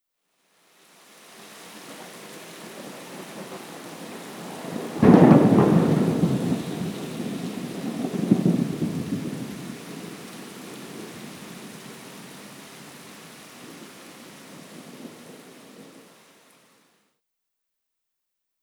LDmkBEKCfZX_LDmkAvn3vxX-mixkit-rain-and-thunder-crash-1258.wav